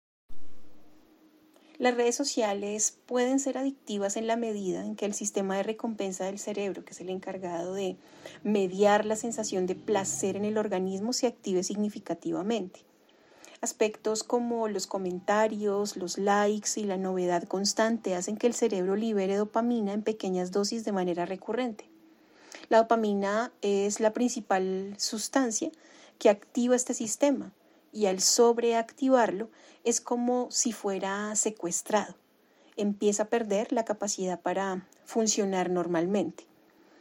Hemos conversado